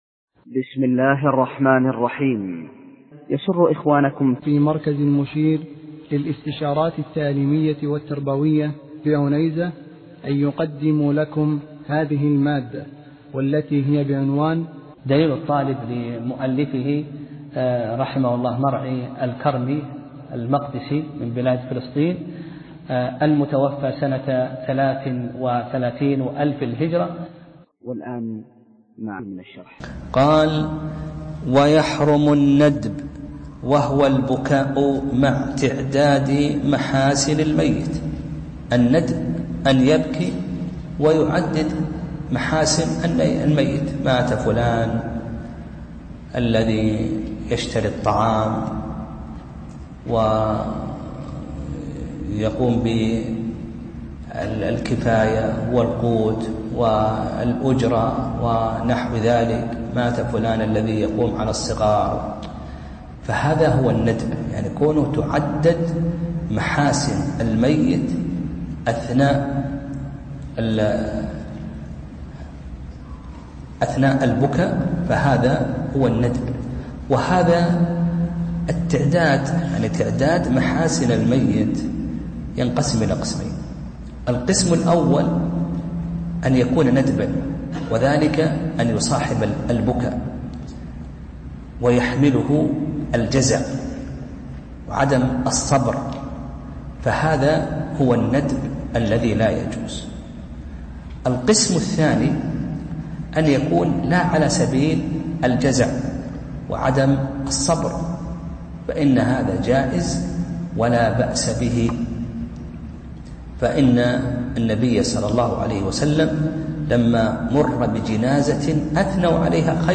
درس (31) : كتاب الجنائز (7)